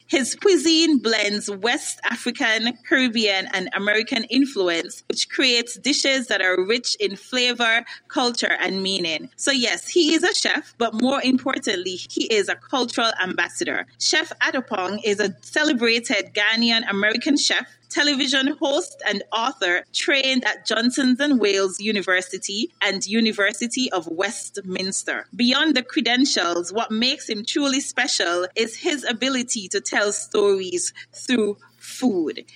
The information was revealed during a Press Launch on Friday, April 24th at the Malcolm Guishard Recreational Park at Pinneys.